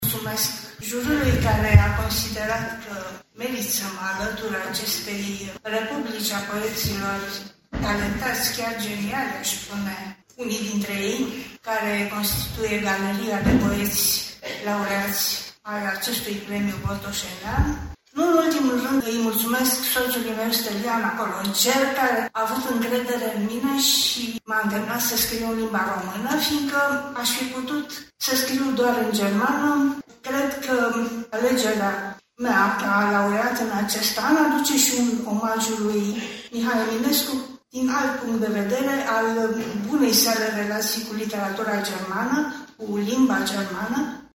Distincția i-a fost înmânată, în această seară, pe scena Teatrului „Mihai Eminescu” din Botoșani: